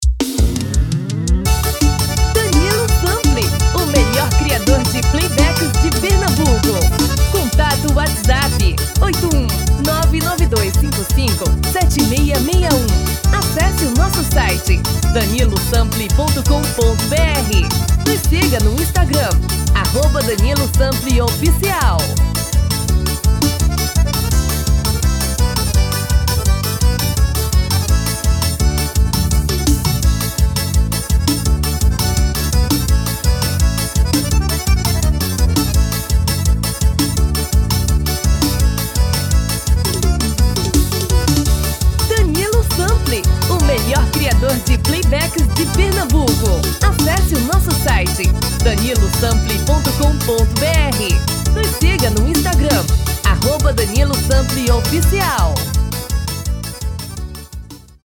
DEMO 1: tom original / DEMO 2: tom masculino